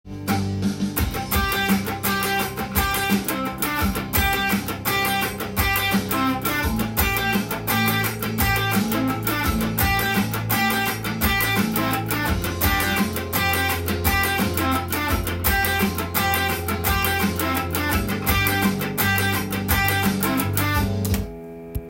Cメジャーペンタトニック（Amペンタトニックスケール）
ファンキーなギターパート例
①のパートのようにGの音を頻繁に使います。
cutting.riff1_.m4a